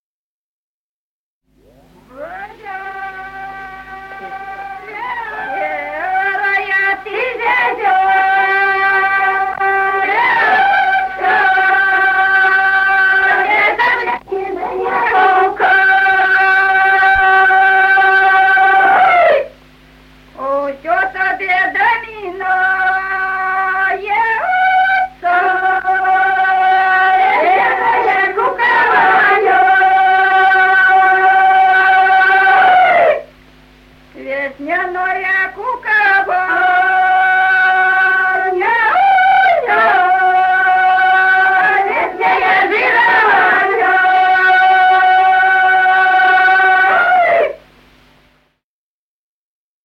Народные песни Стародубского района «Серая ты зязюлечка», жнивная.
1959 г., с. Курковичи.